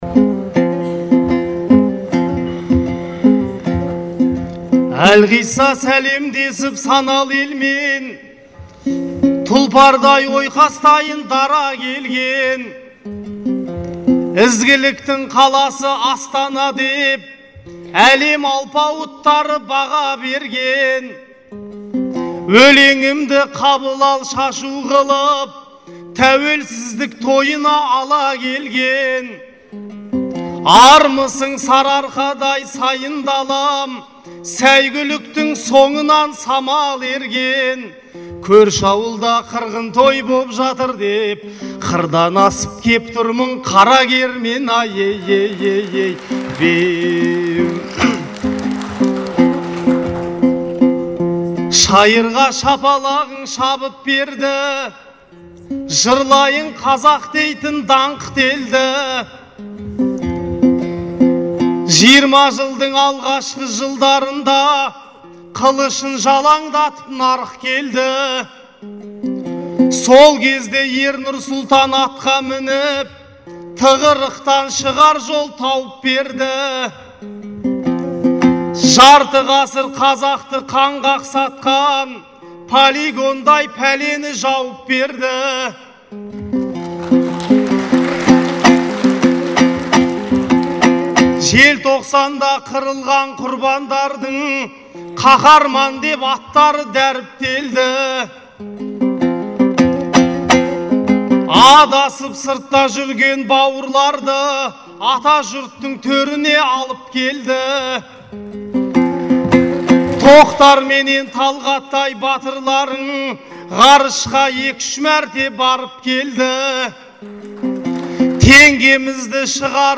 Астанадағы айтыс